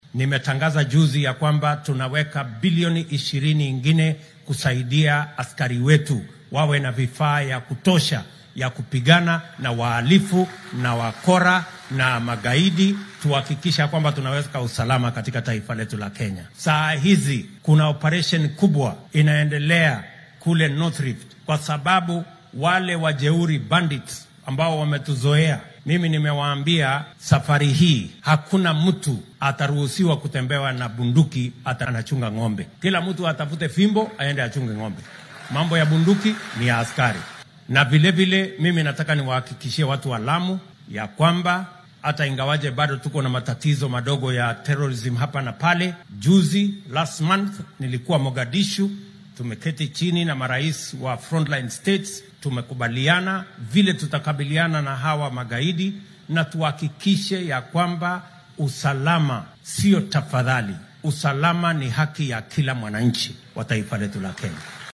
Madaxweynaha dalka William Ruto ayaa ka hadlay arrimaha ammaanka isagoo sheegay in dowladda ay gacanta ku dhigi doonto qoriyaha ay sitaan shacabka gaar ahaan waqooyiga gobolka Rift Valley oo ay falal burcadnimo ku soo noqnoqdeen.